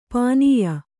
♪ pānīya